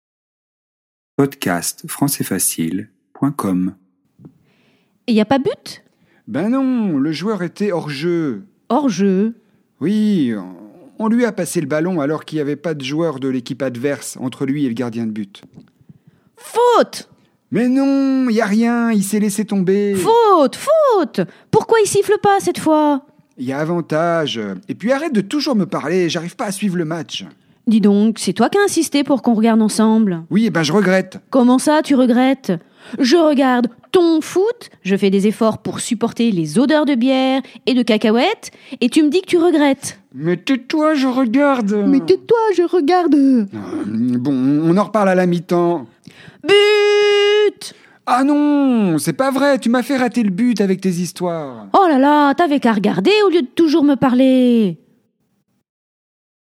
• français familier
🔷  DIALOGUE :